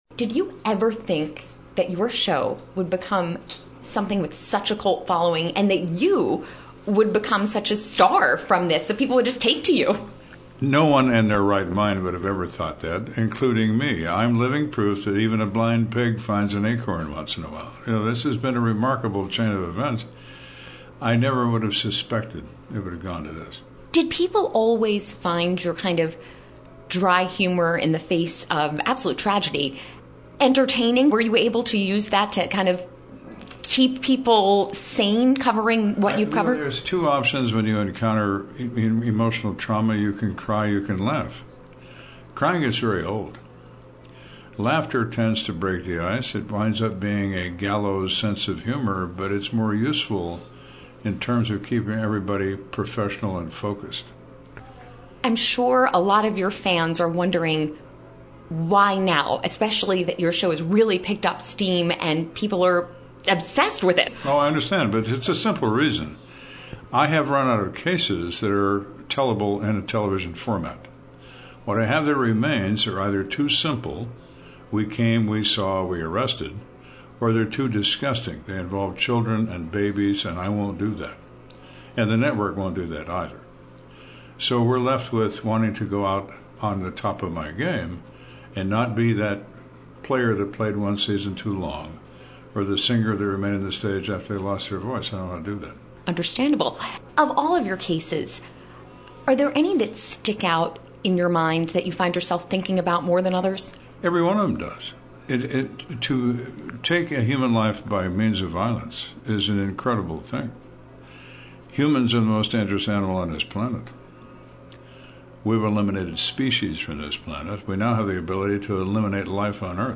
Kenda told WMAL during his retirement party at the Death Becomes Us –True Crime Festival at George Washington University Nov. 9th, he decided it’s time to end the popular show for a simple reason: he’s run out of cases that work for TV.